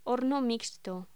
Locución: Horno mixto
voz